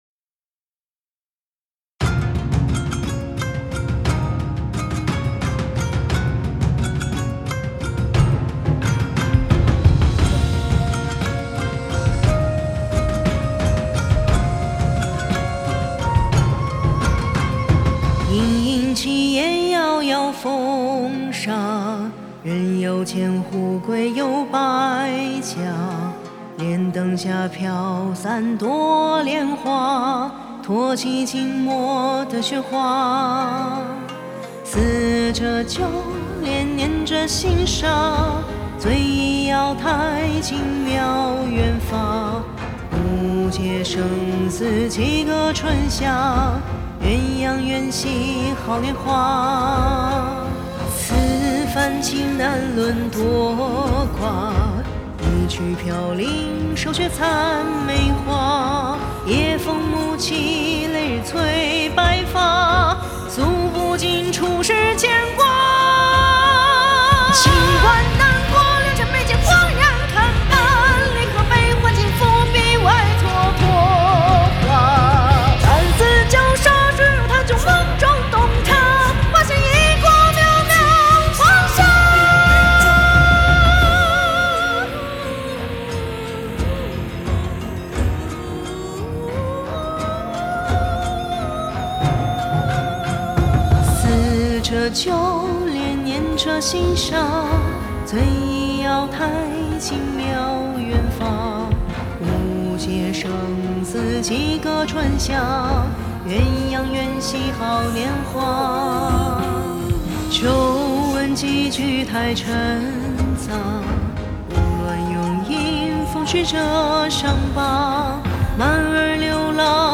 翻唱制作